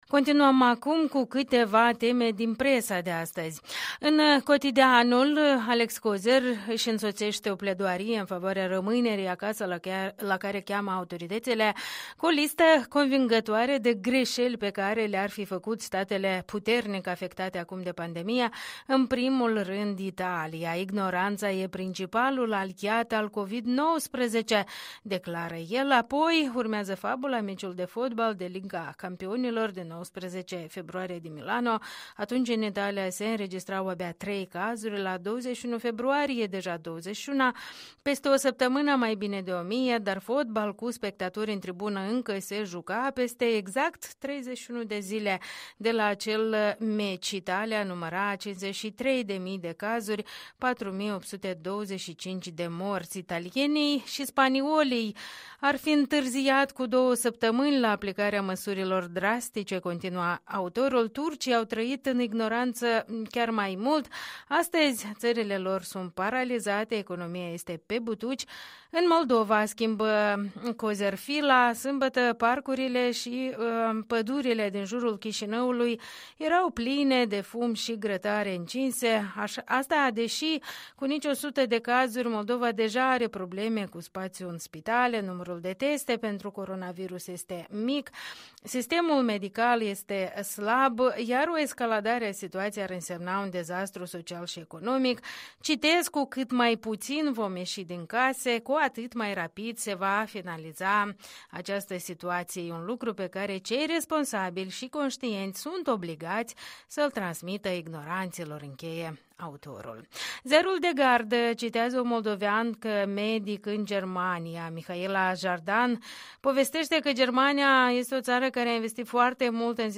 Europa Liberă, revista presei matinale, luni 23 martie 2020